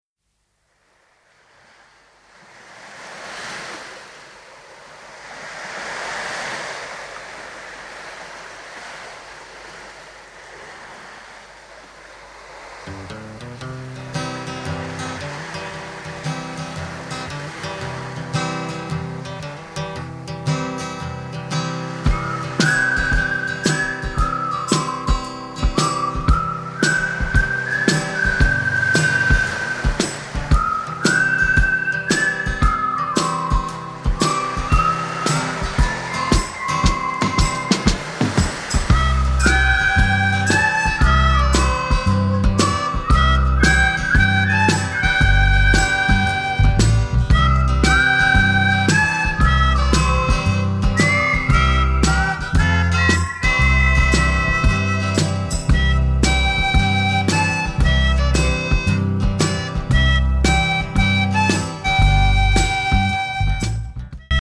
drums
elec.base/pianica/whistle solo
elec.guitar/fork guitar/sitar